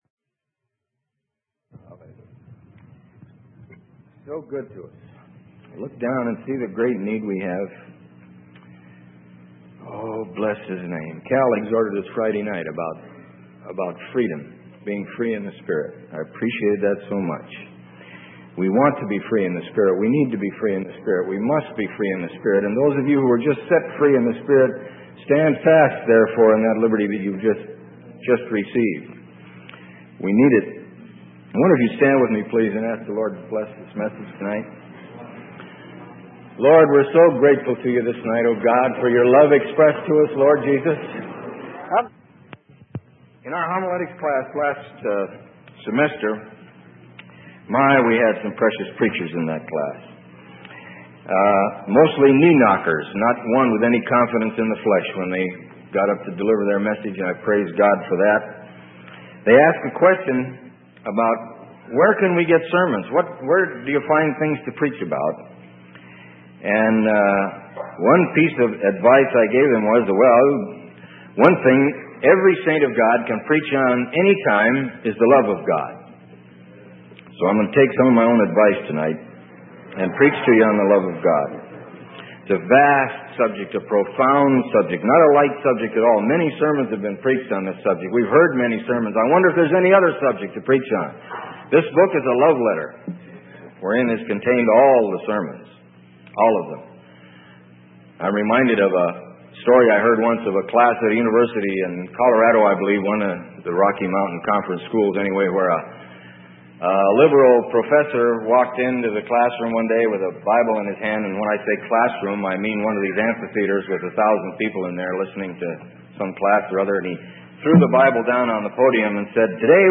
Sermon: The Pursuit of Love - Freely Given Online Library